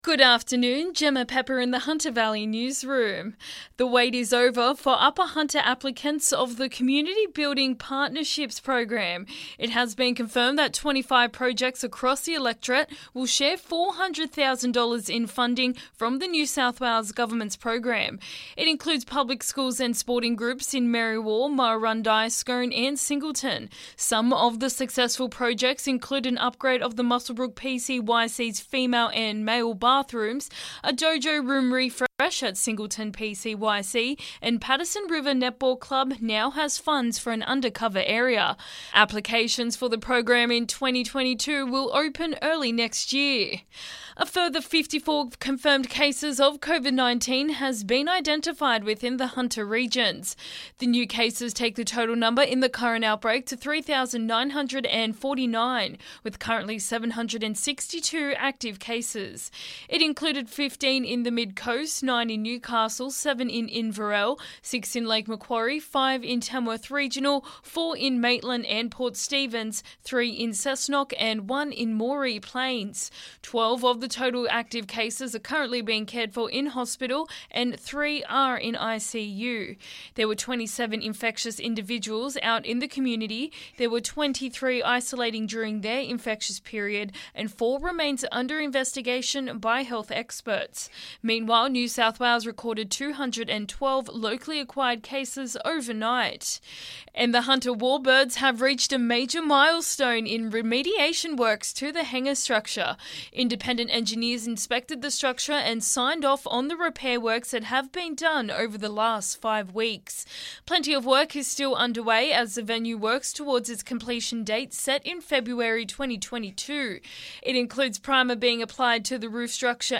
LISTEN: Hunter Valley Local News Headlines 16/11/2021